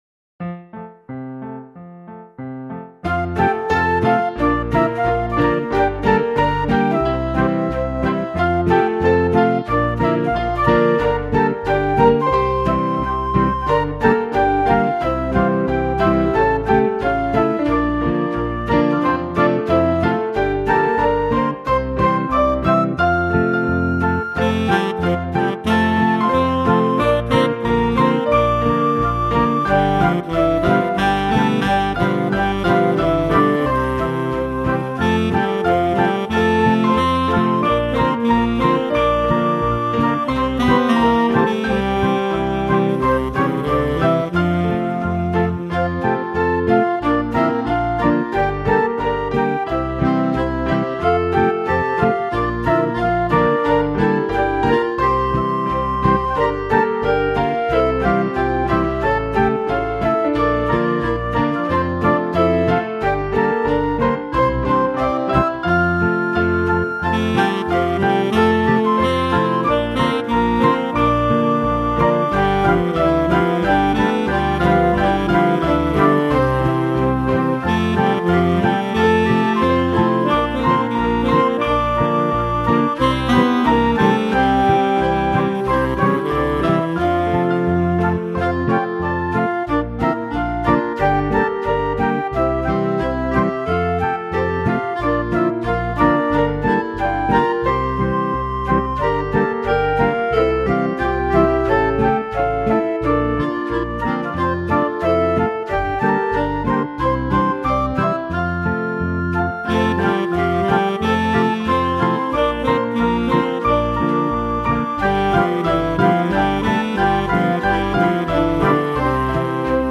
Og her følger den anden nye julemelodi: